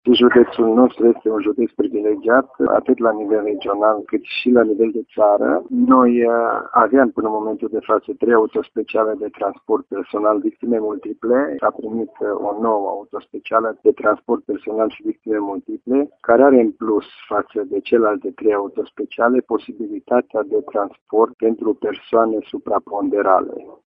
Șeful Inspectoratului pentru Situații de Urgență ”Horea” Mureș, Dorin Oltean: